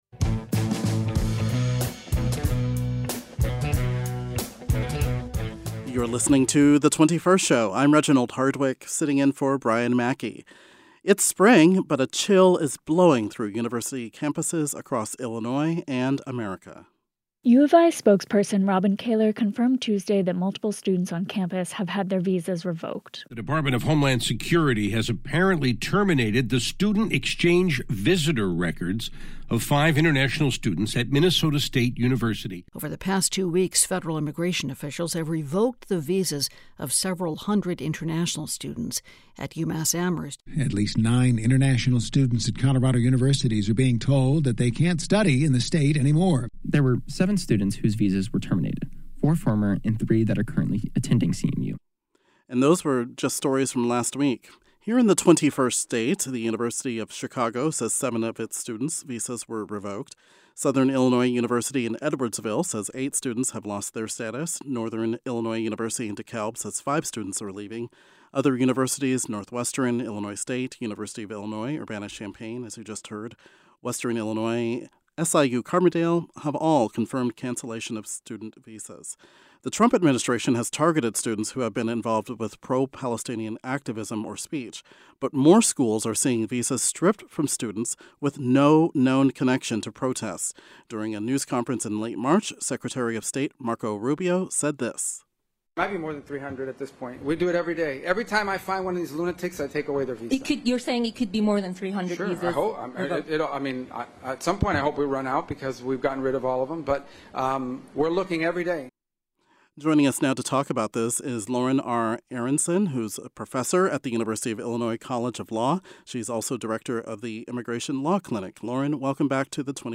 A journalist who covers education and representatives from various universities in the state discuss how the universities are responding to this crackdown and how international students are feeling in this current political climate.